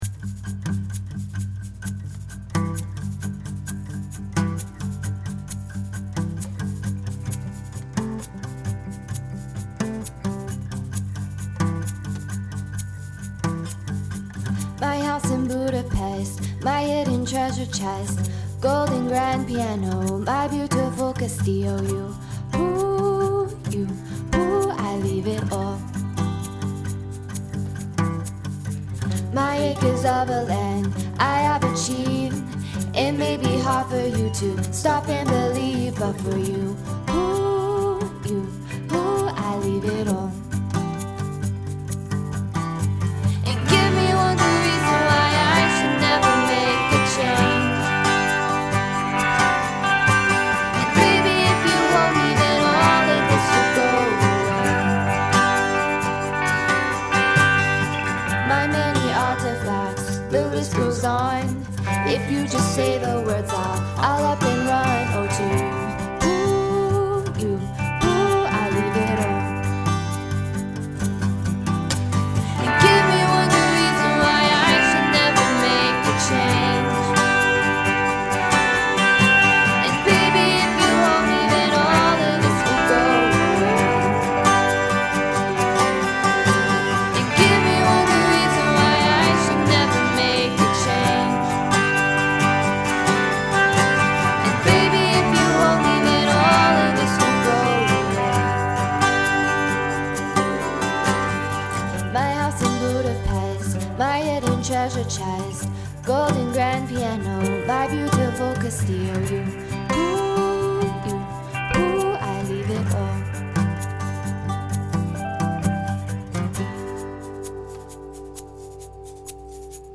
This is also his first time singing (background).
lap steel